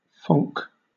Ficheiro de áudio de pronúncia.